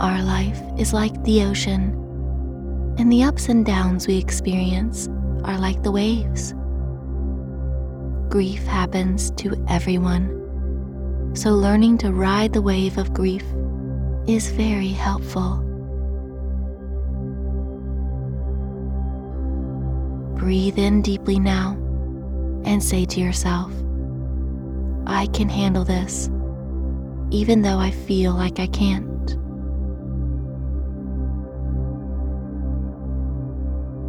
This is a short 6 minute session, ideal for a daily listen, preferably in the evening before bed or when ever you are over come by grief or sorrow. let the hypnosis reprogram your subconscious and unleash it’s full power.